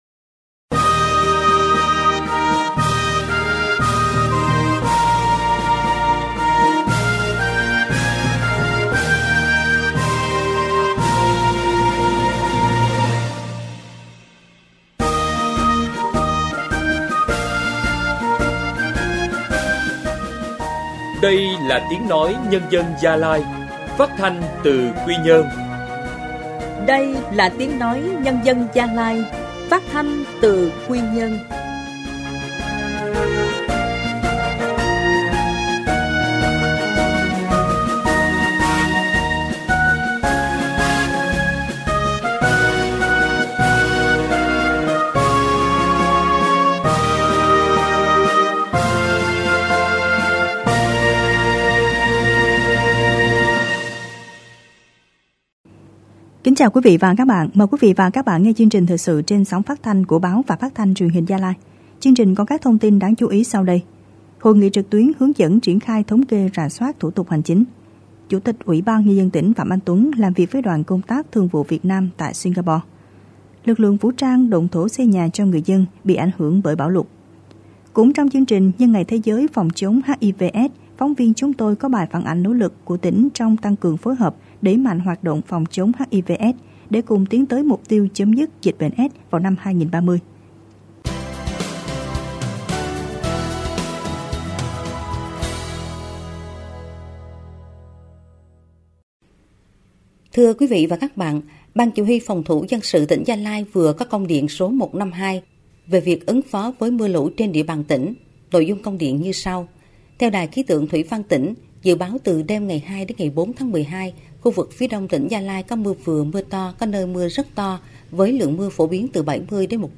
Thời sự phát thanh sáng